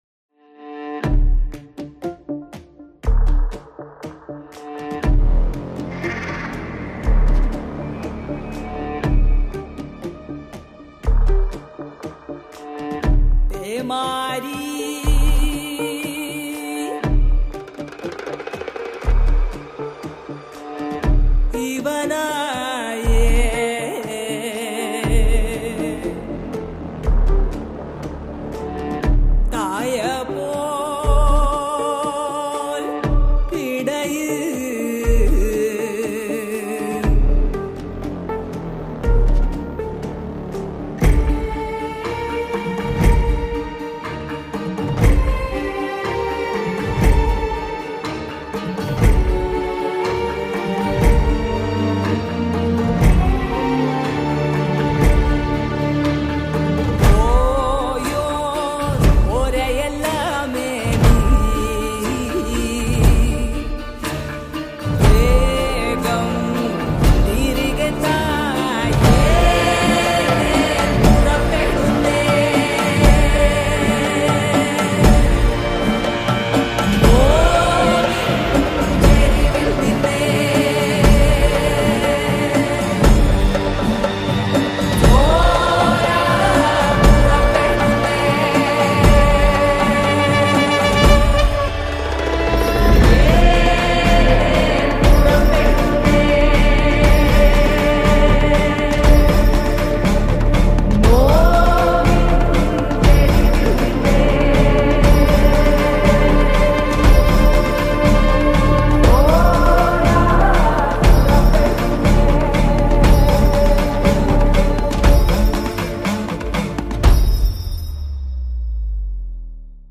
emotional Malayalam track